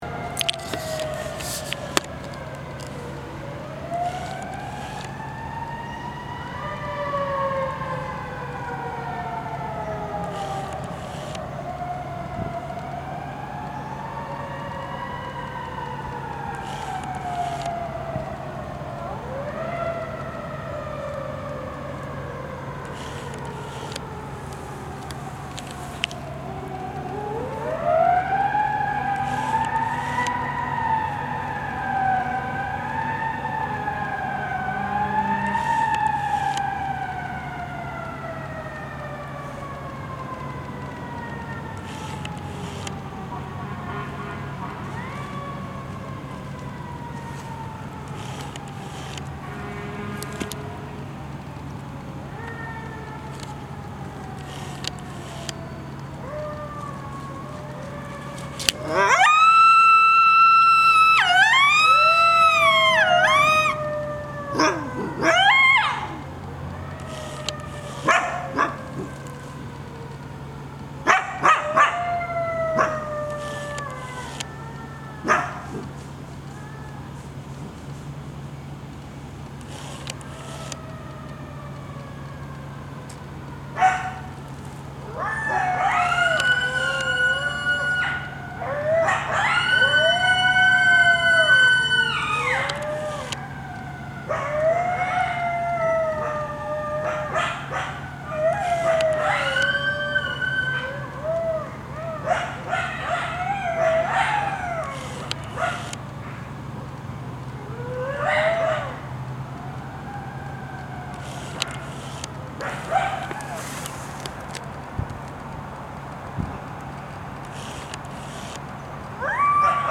Right before Dad disappeared, during this time period of upset, while collecting my camera during the dark hours before dawn, I came to hear one of their family’s very conversational vocalization sessions, but Dad is not heard among the group.
Howls: family conversation before Monte leaves LHS: